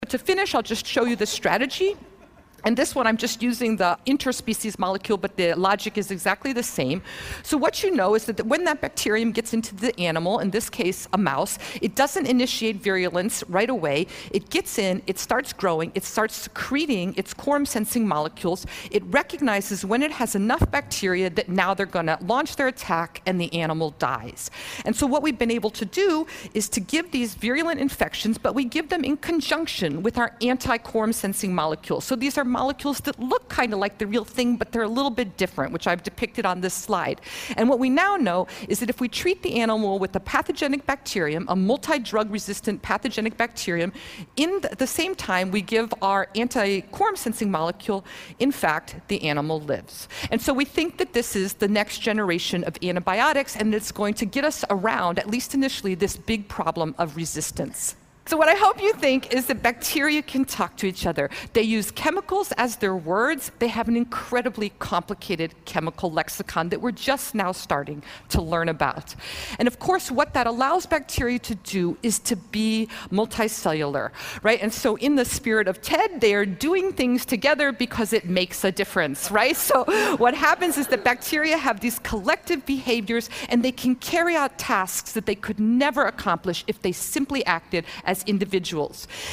TED演讲：细菌是怎样交流的(10) 听力文件下载—在线英语听力室